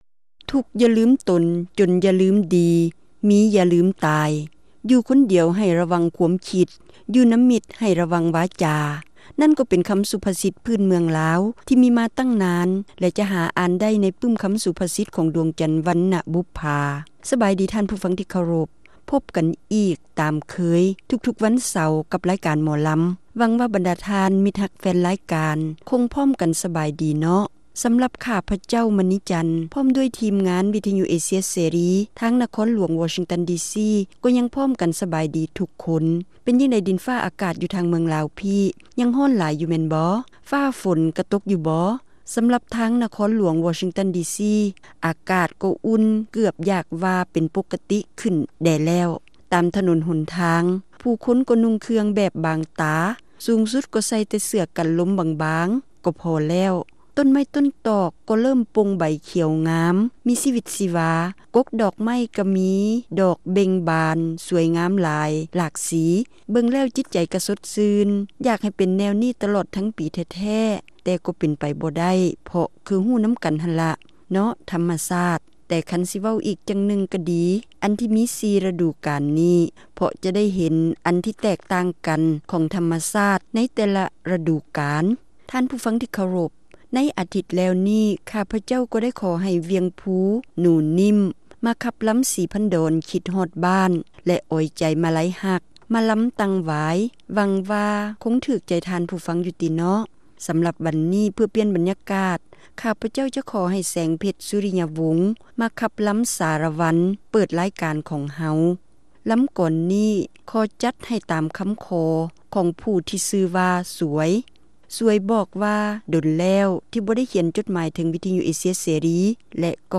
ຣາຍການໜໍລຳ ປະຈຳສັປະດາ ວັນທີ 4 ເດືອນ ພຶສພາ ປີ 2007